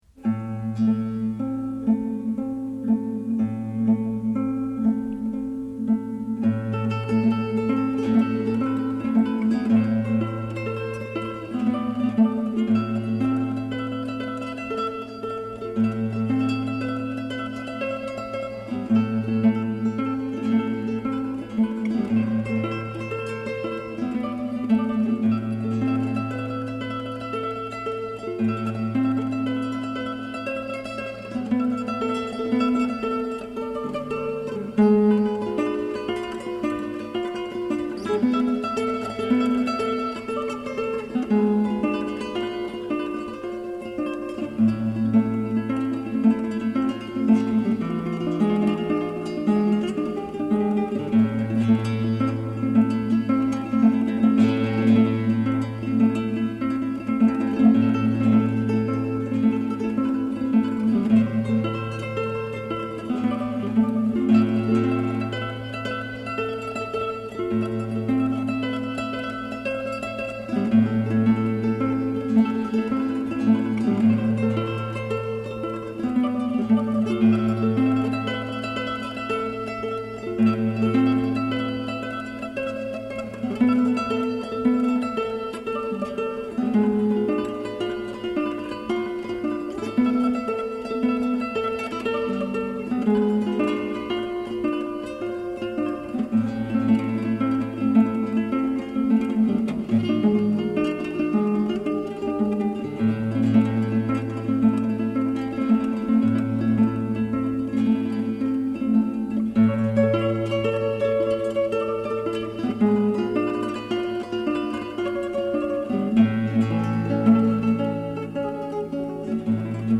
Classical guitar pieces